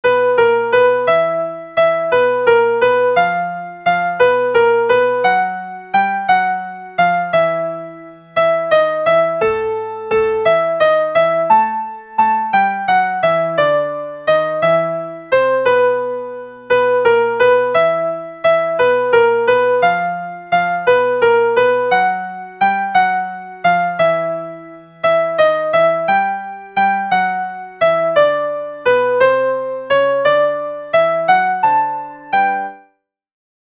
Le chœur des invités
Soprani
elle_danse_soprani.mp3